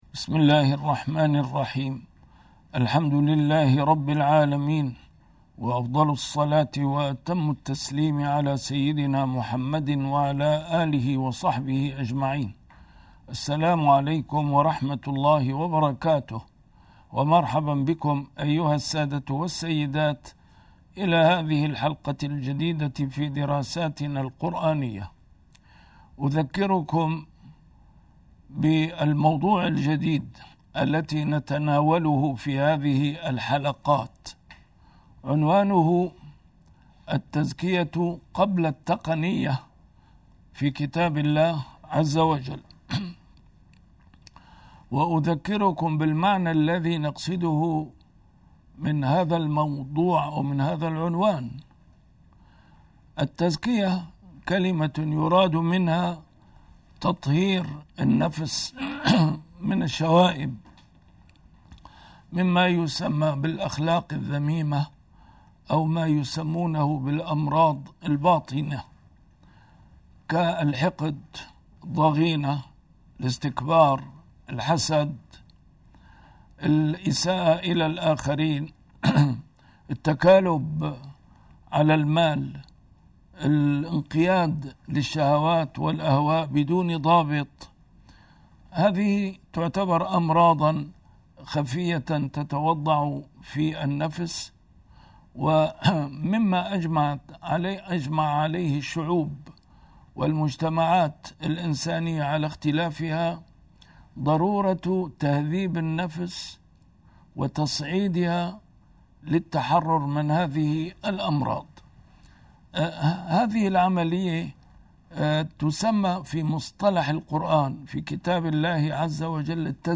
A MARTYR SCHOLAR: IMAM MUHAMMAD SAEED RAMADAN AL-BOUTI - الدروس العلمية - التزكية في القرآن الكريم - 3 - السبيل إلى تزكية النفس